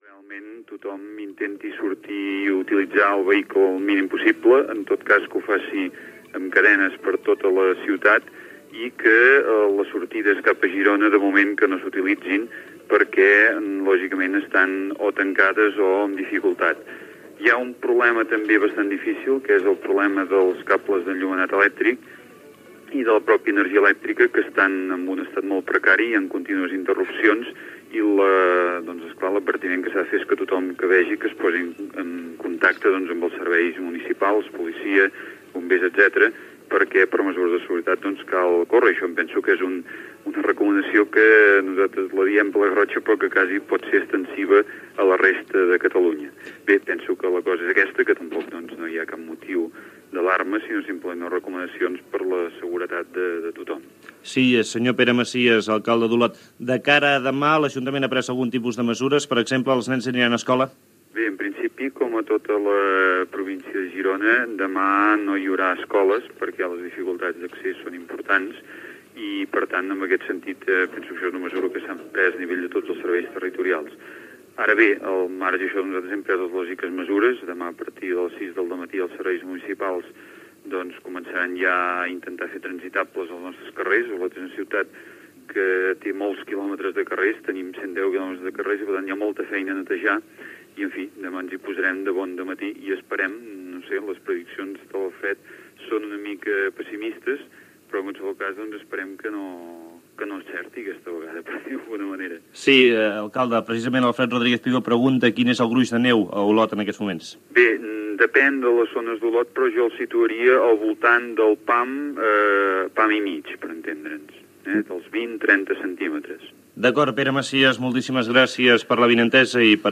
Trucada telefònica des d'Espinelves.